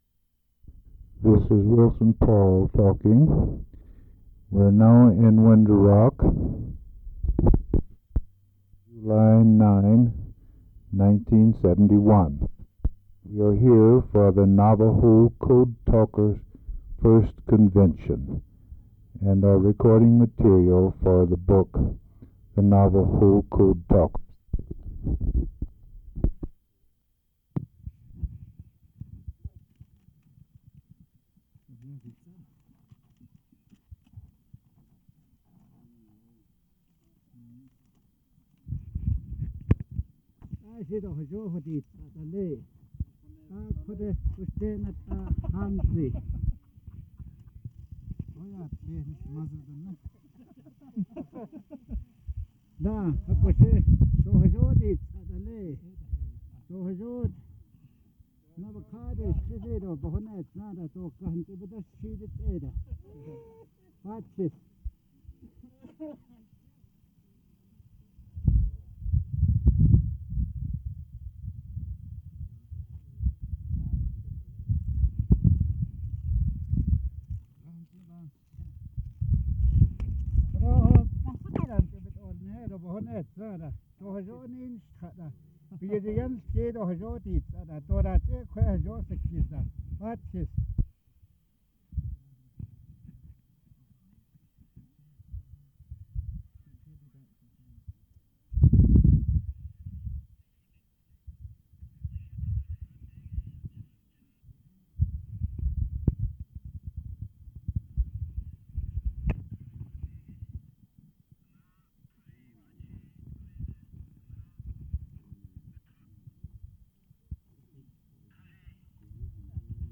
Subjects: Military, World War II, Navajo code talkers, Navajo Indians, Interviews
Audio/mp3 Original Format: Audio cassette tape Resource Identifier